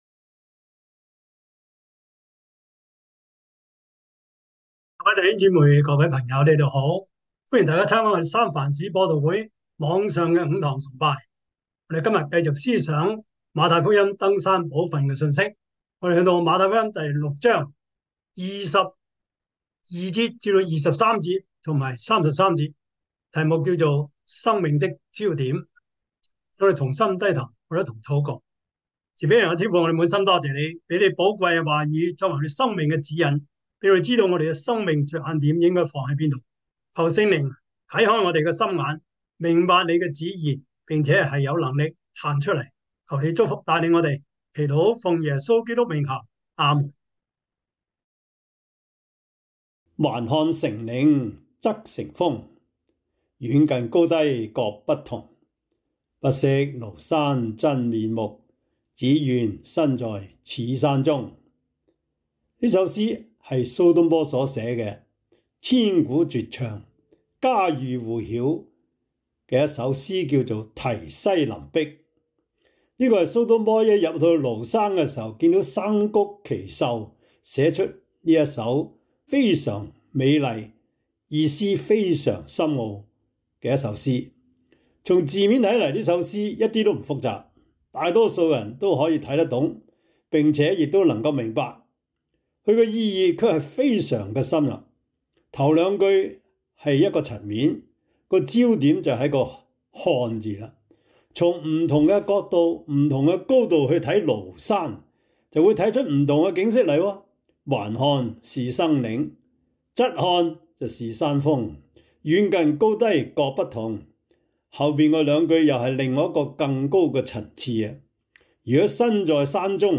33 Service Type: 主日崇拜 馬太福音 6:22-23
Topics: 主日證道 « 屬靈領袖 快樂人生小貼士 (二) – 第十五課 »